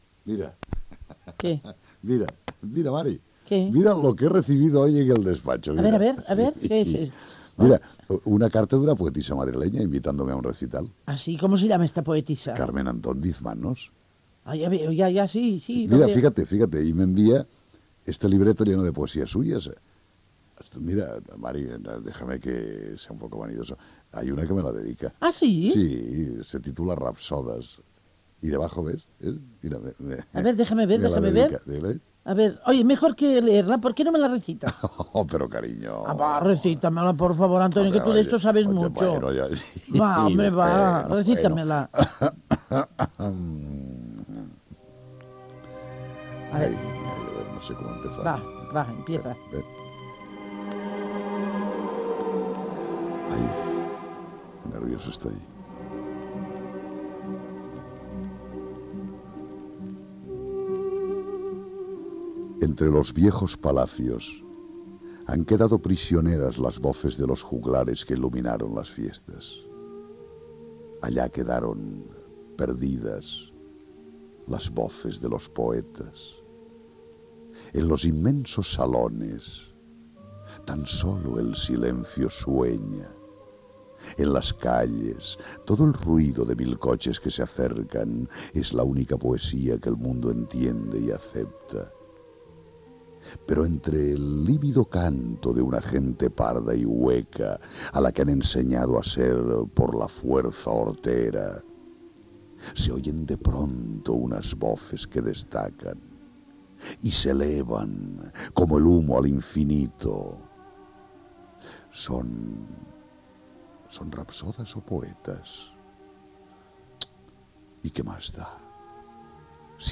Diàleg, recitat d'una poesia, segueix el diàleg entre els presentadors, recitat d'una poesia, els presentadors planegen un viatge a Marbella Gènere radiofònic Entreteniment